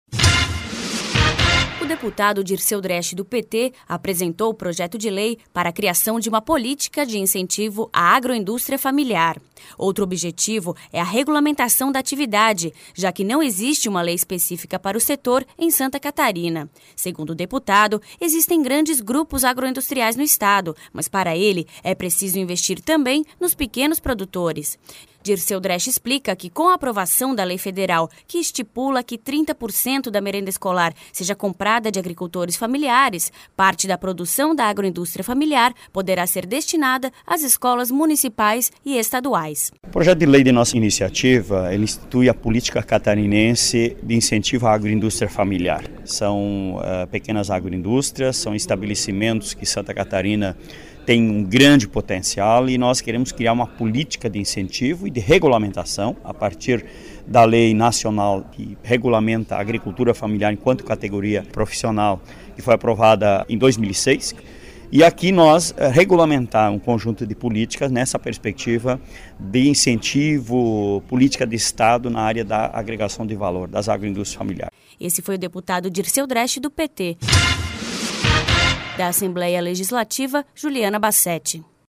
Apresentação da repórter